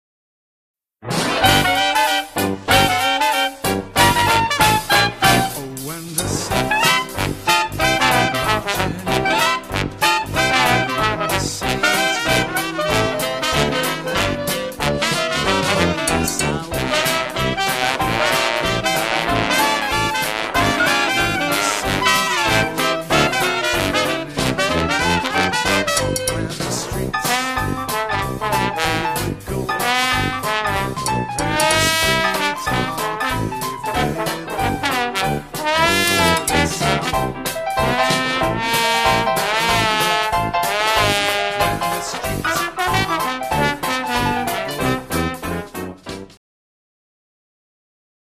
Backing track
The backing track is a great - BUT a little quick!